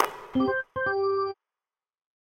Sfx Make A Basket Sound Effect
sfx-make-a-basket-1.mp3